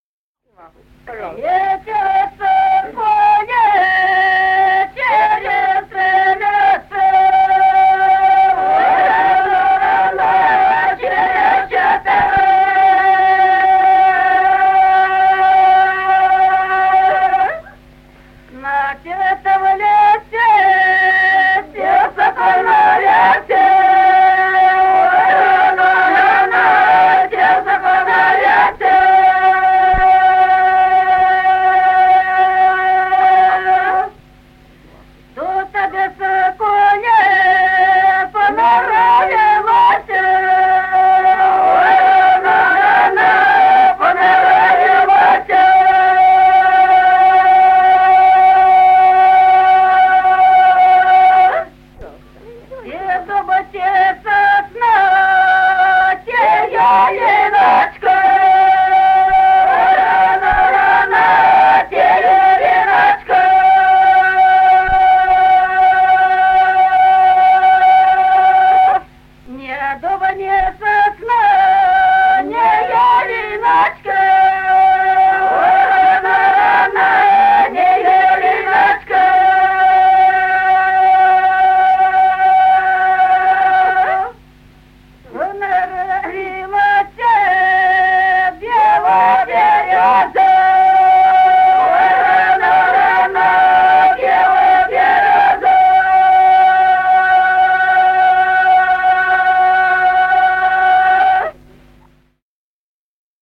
Песни села Остроглядово. Летел соколик И0443-14